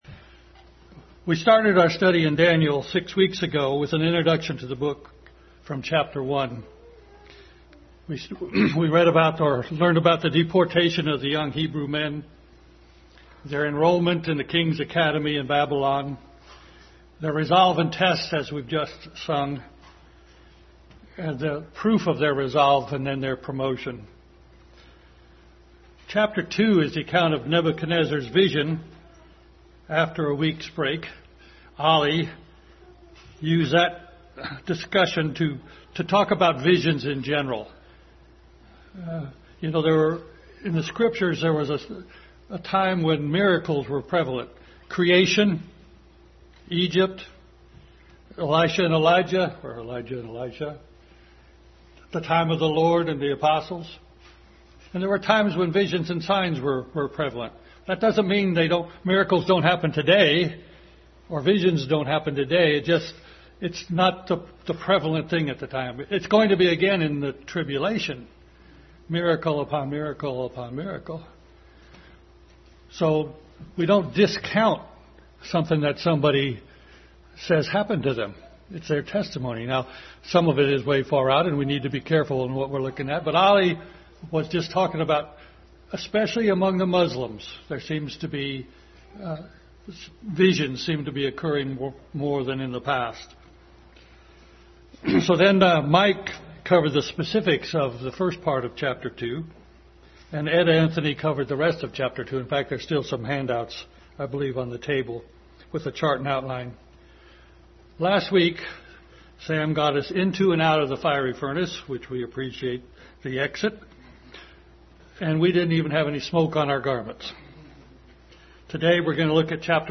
Daniel 4:1-37 Passage: Daniel 4:1-37, Jeremiah 51:1-10, Deuteronomy 29:19, Psalm 107, 2 Samuel 12 Service Type: Family Bible Hour